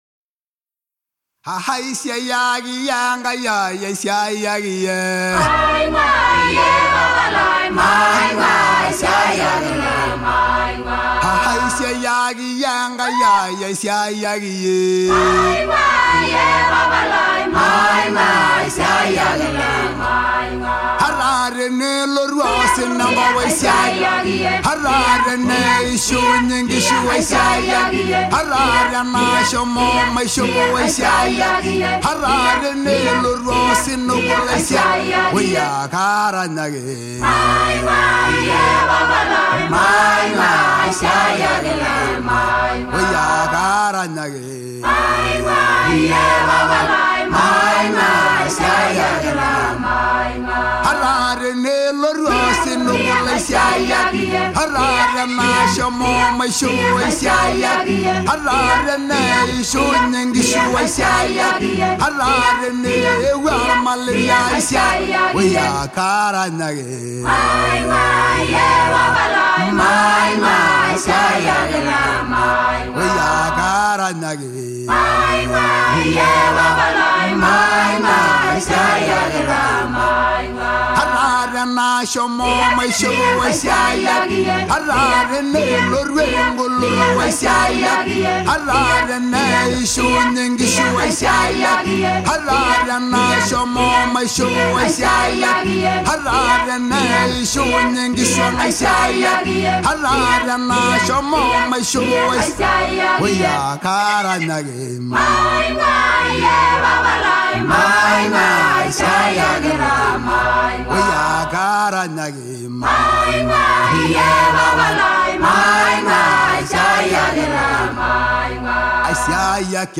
Maasai Worship